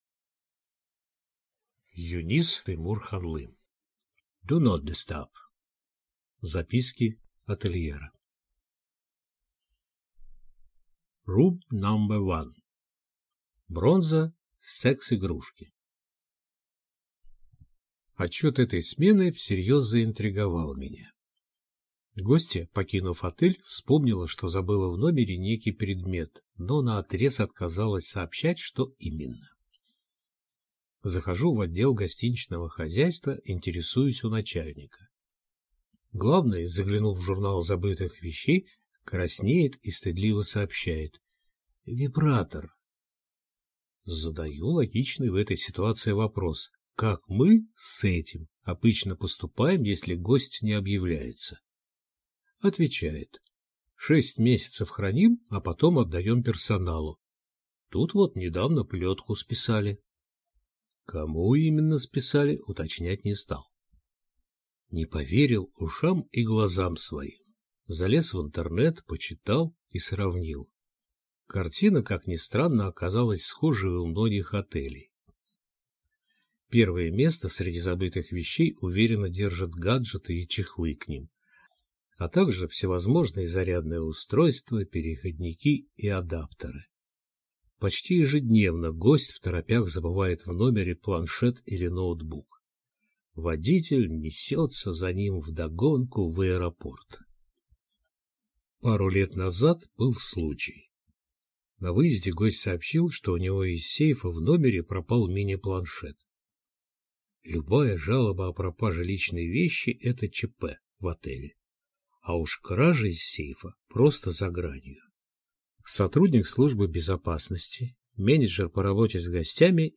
Аудиокнига «Do not disturb». Записки отельера | Библиотека аудиокниг